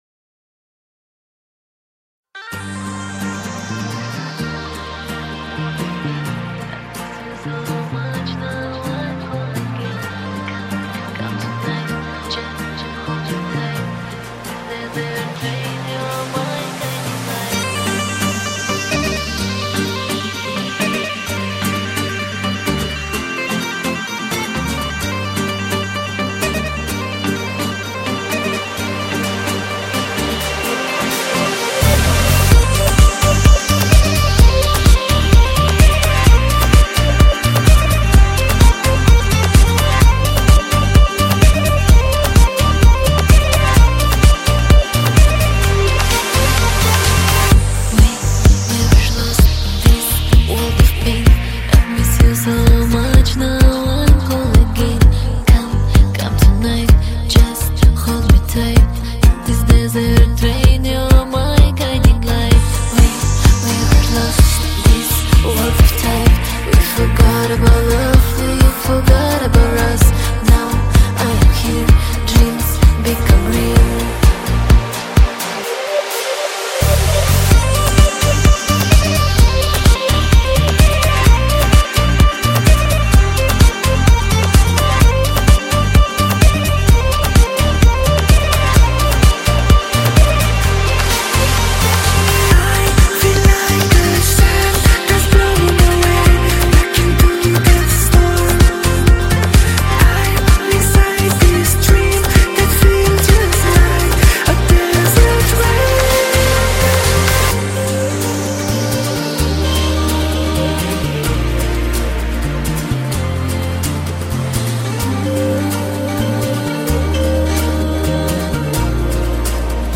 Категория: House